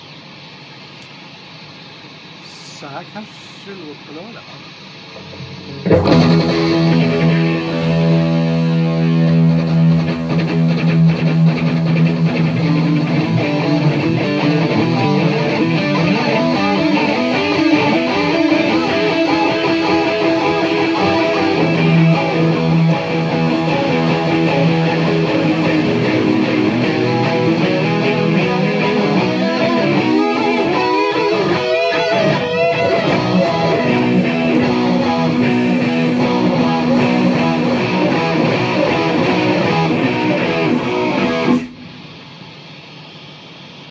Delays